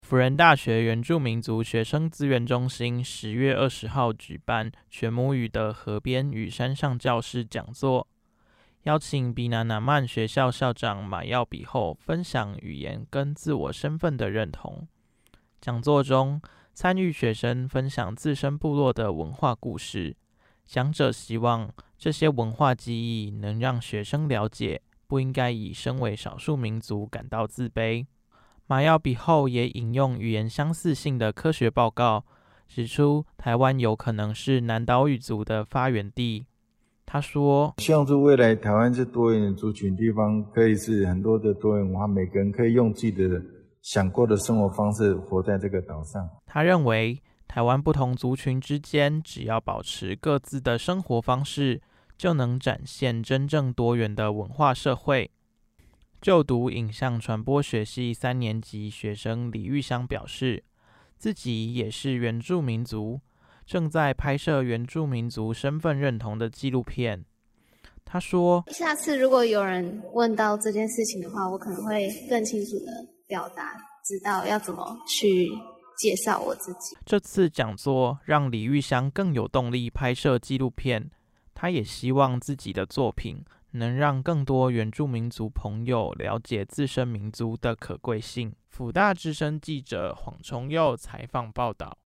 原住民族話帶_mixdown.mp3